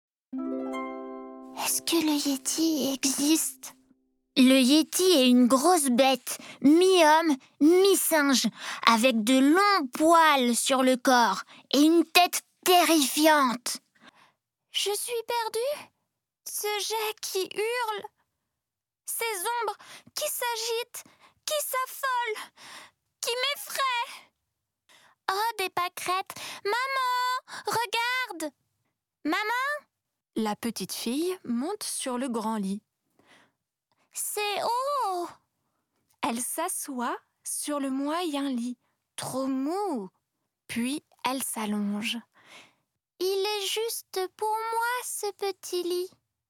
Démo voix d'enfants